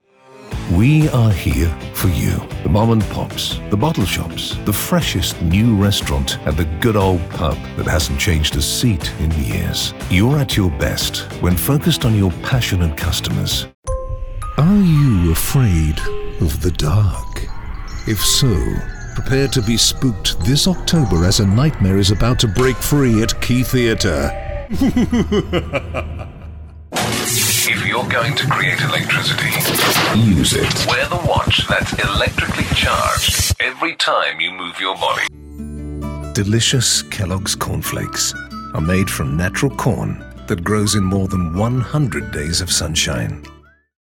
Englisch (Britisch) Synchronsprecher